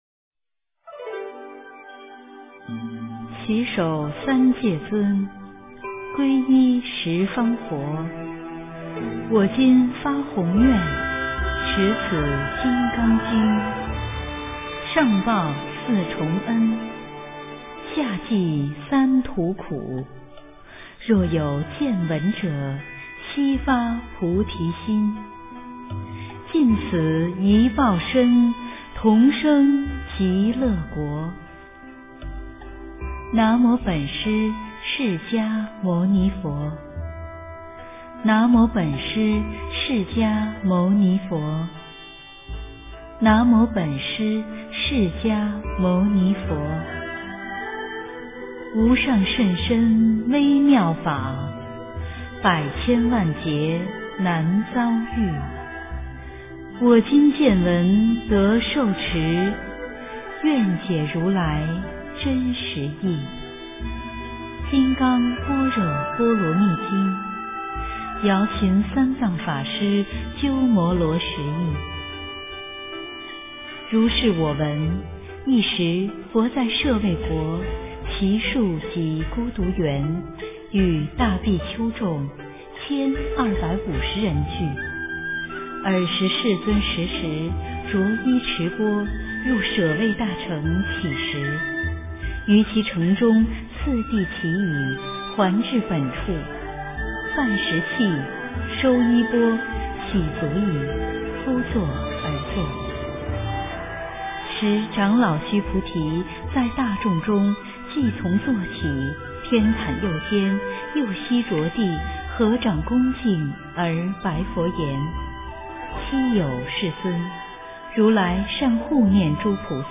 诵经
佛音 诵经 佛教音乐 返回列表 上一篇： 达摩祖师血脉论 下一篇： 佛说十二佛名神咒校量功德除障灭罪经 相关文章 祈祷药师佛--Lama Gyurme 祈祷药师佛--Lama Gyurme...